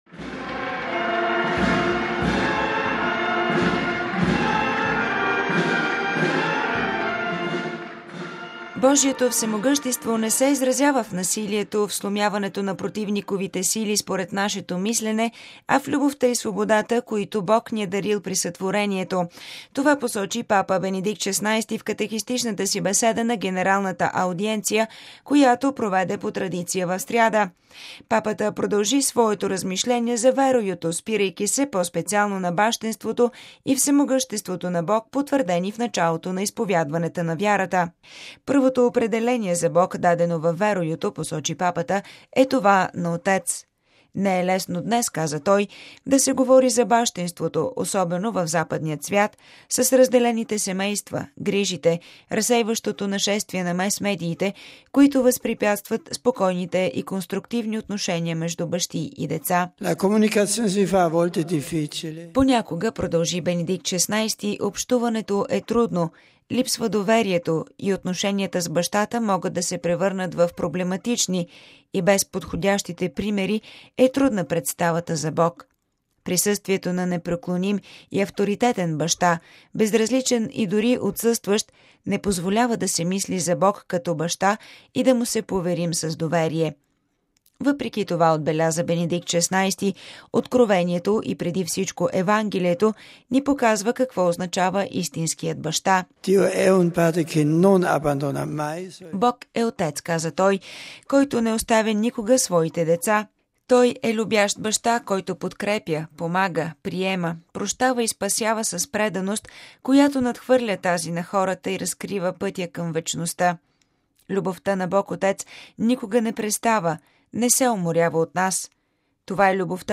Това посочи Папа Бенедикт ХVІ в катехистичната си беседа на генералната аудиенция, която проведе по традиция в сряда. Папата продължи своето размишление за „Веруюто”, спирайки се по-специално на бащинството и всемогъществото на Бог, потвърдени в началото на изповядването на вярата.